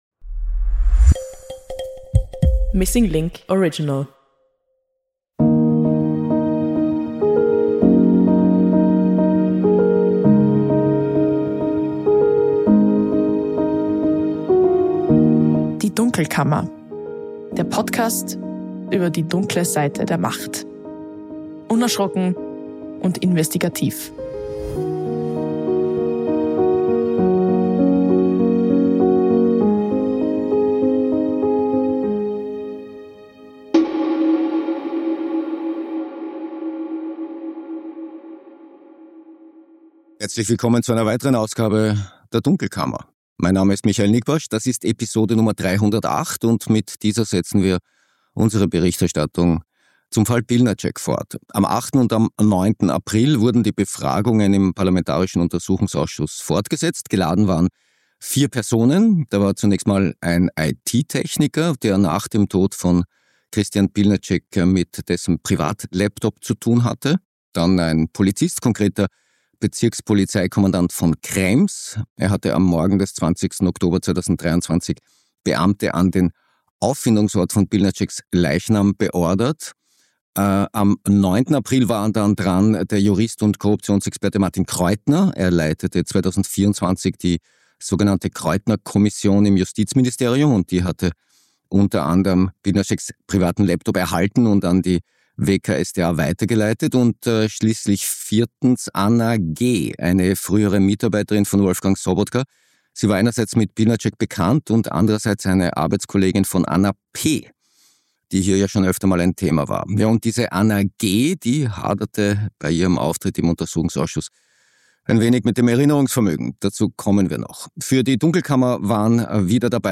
Im Studio